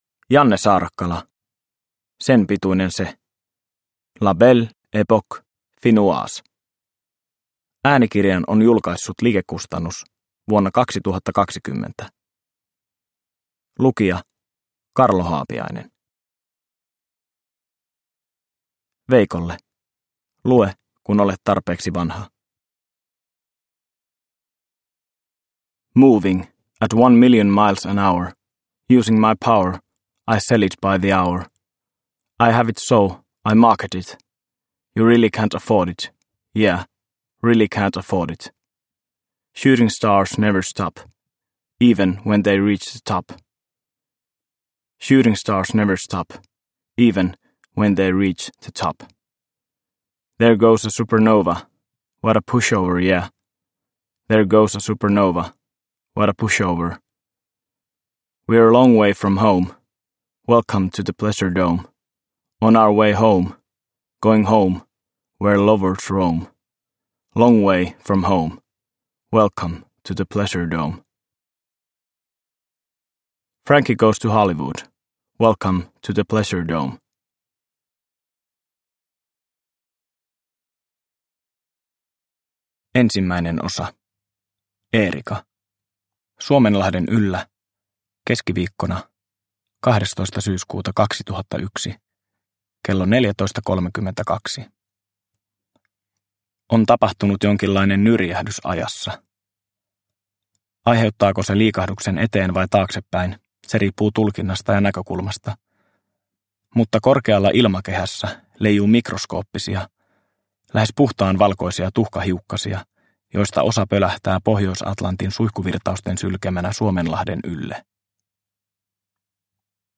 Sen pituinen se – Ljudbok – Laddas ner